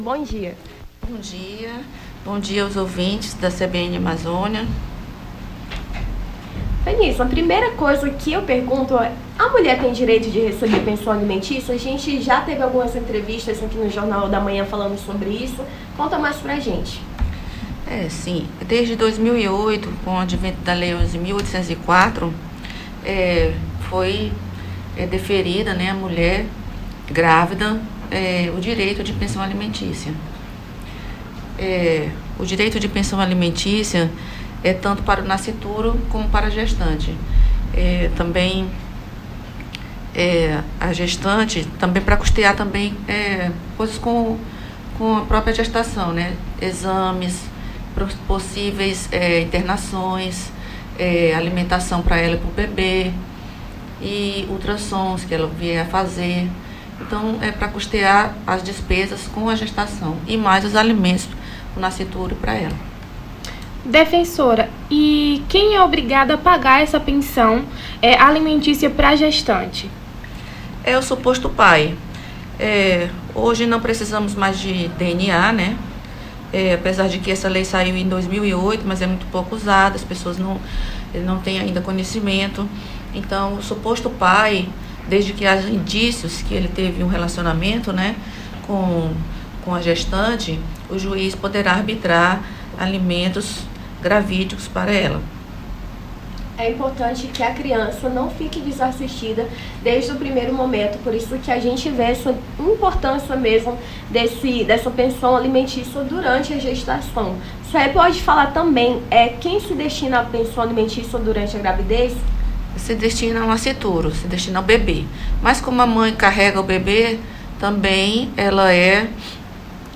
Nome do Artista - CENSURA - ENTREVISTA (SEUS DIREITOS COM A DP) 28-08-23.mp3